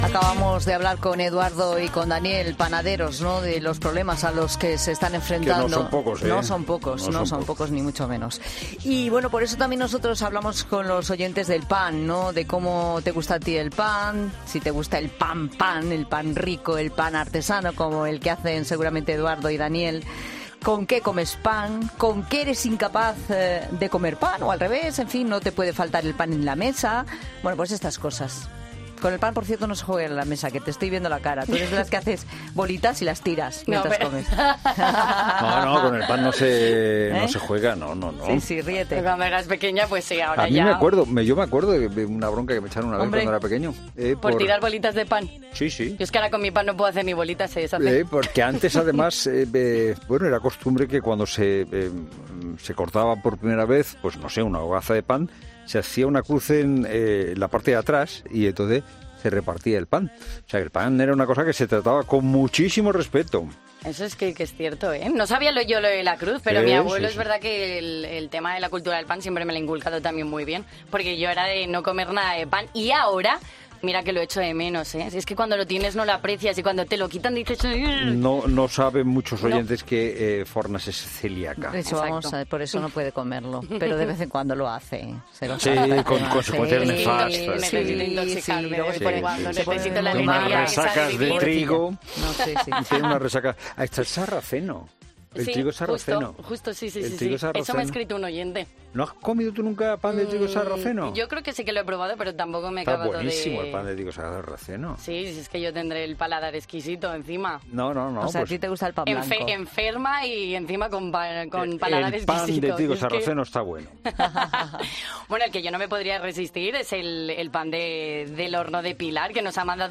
Y todo ello en el marco del programa de este jueves, cuando los oyentes se lanzaban a contar al equipo del programa sus hábitos y preferencias con uno de los alimentos más habituales en la mesa.